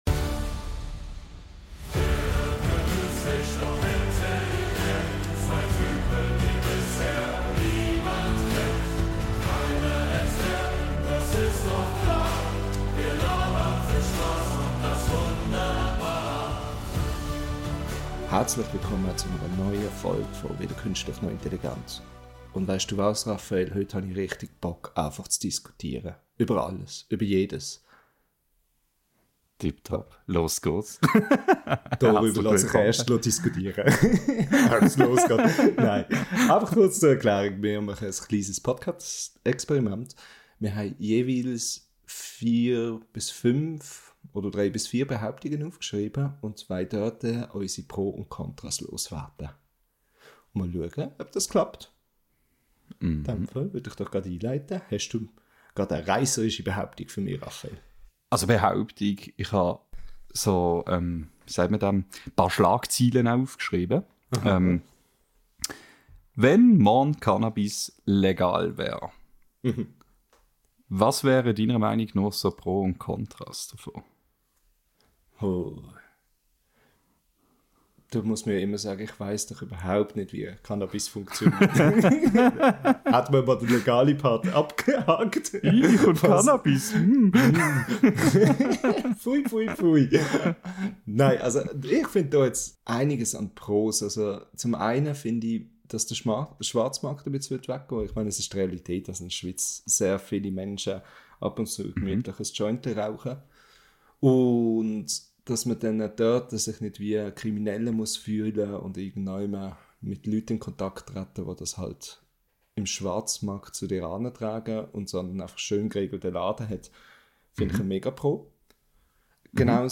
In dieser neuen Folge unseres schweizerdeutschen Podcasts wird diskutiert, debatiert und philosophiert! Soll wirklich jeder Mensch auf dieser Welt einen Fiat Multipla besitzen?